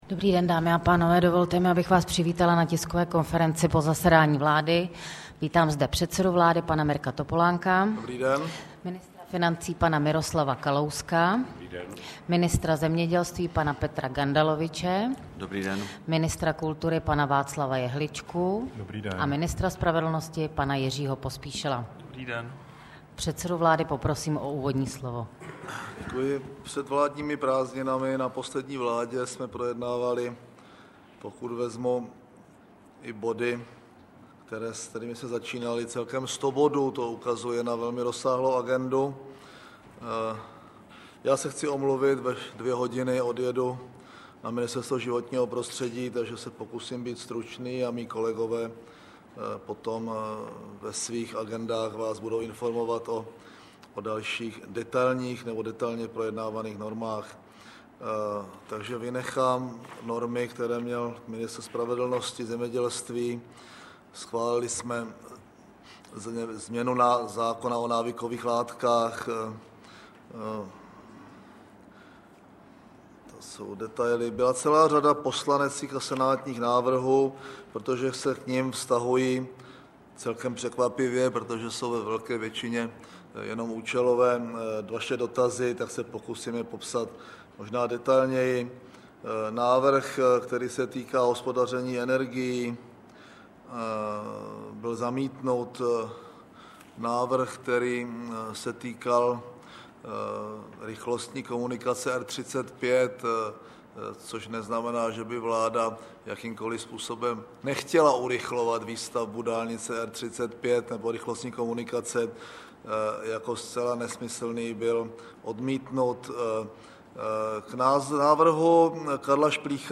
Tisková konference po jednání vlády ČR 23. července 2008